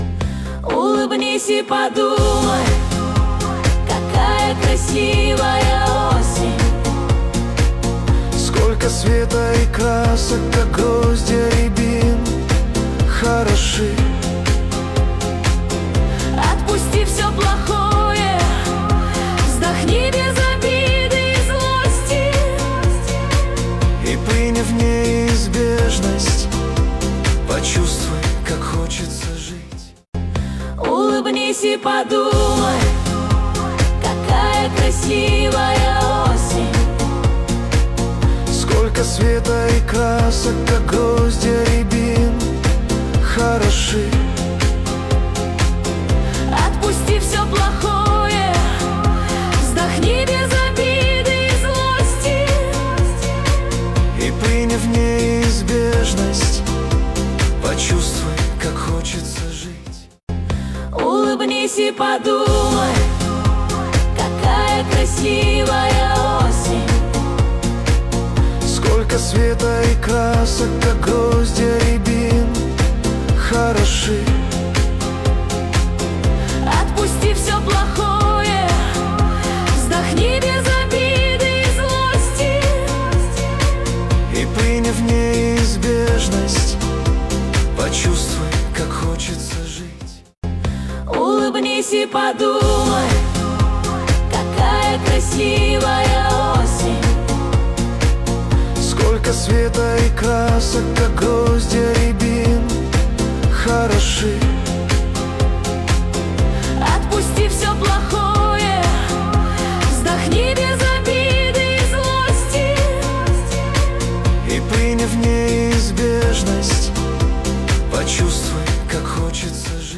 Песня ИИ нейросеть